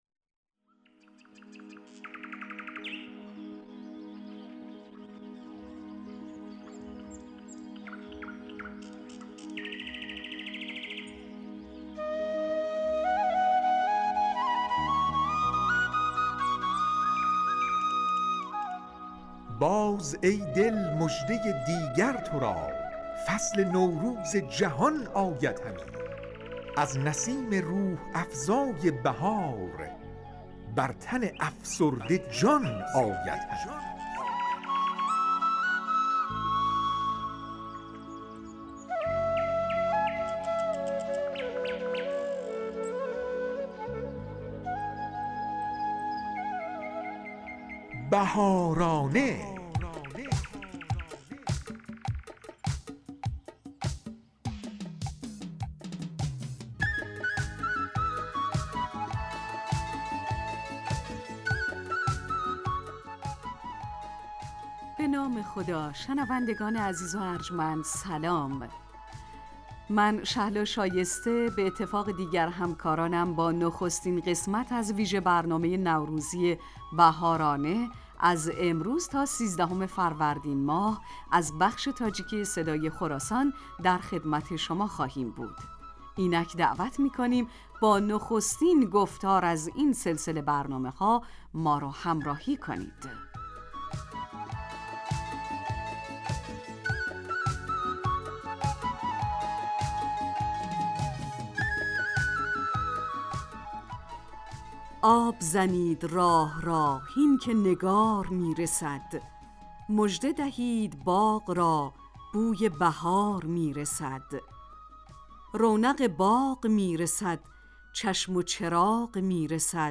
"بهارانه" ویژه برنامه نوروزی رادیو تاجیکی صدای خراسان است که به مناسبت ایام نوروز در این رادیو به مدت 30 دقیقه تهیه و پخش می شود.